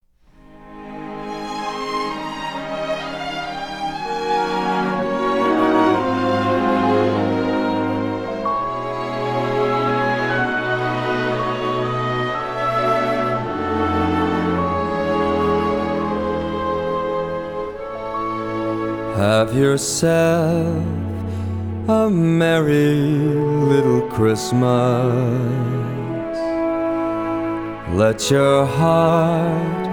Genre: Holiday